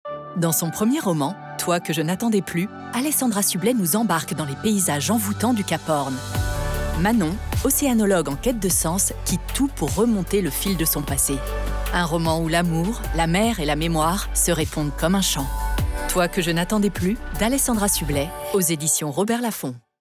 Pub TV / Web Alessandra Sublet voix amicale voix chaleureuse voix mystérieuse Voix mystérieuse Catégories / Types de Voix Extrait : Votre navigateur ne gère pas l'élément video .